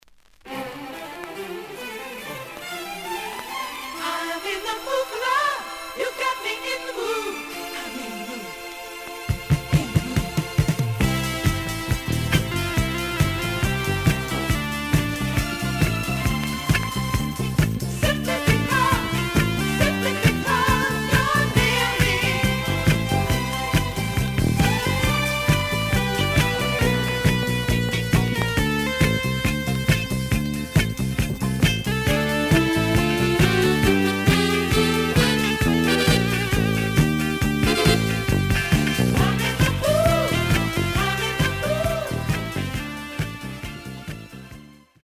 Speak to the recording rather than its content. The audio sample is recorded from the actual item. ●Format: 7 inch Slight edge warp.